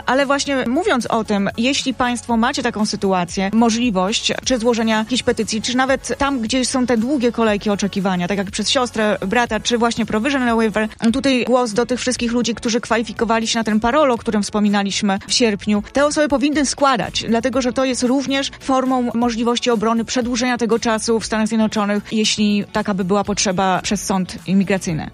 W studiu Radia Deon Chicago